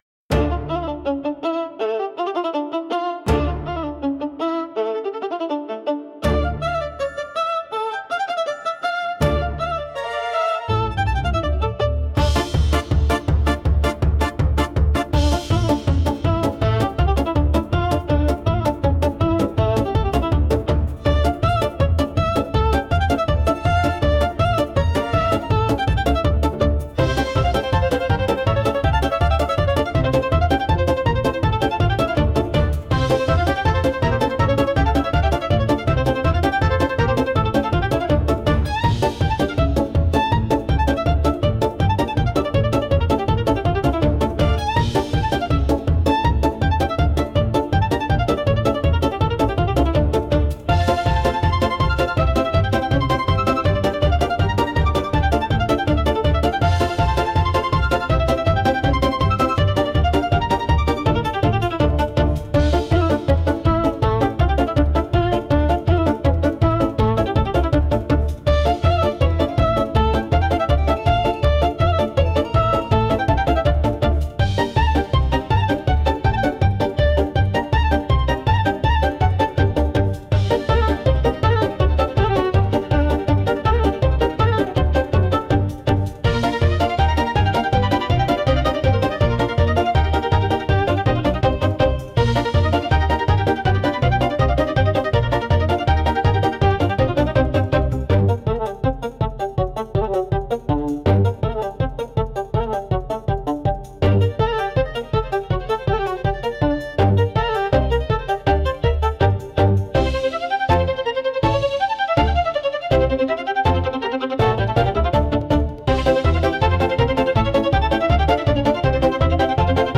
Instrumental / 歌なし
🌍 Lively, bright, and full of rhythm.
軽快なリズムと楽しいメロディが魅力のフォークダンス風ミュージック！
テンポが速くても自然に体が動き出すような、明るくハッピーな一曲です🎶